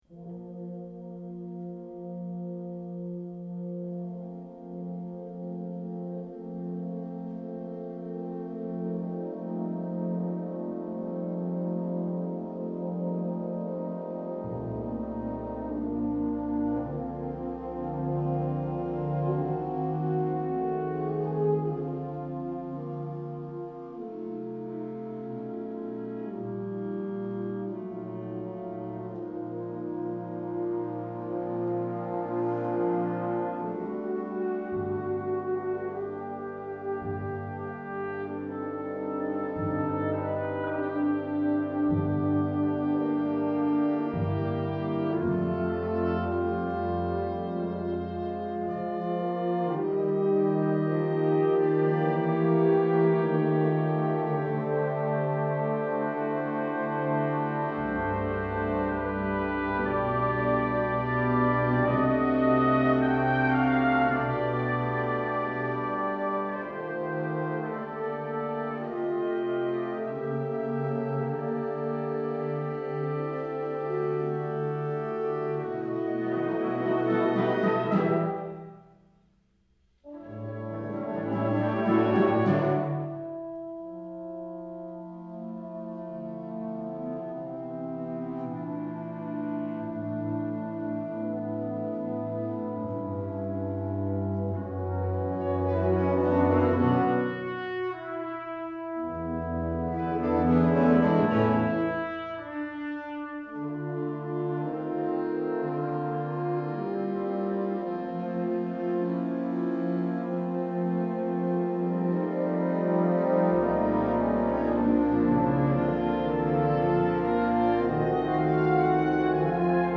Wind ensemble, 2018
Piano
Timpani
5 Percussion
Double-Bass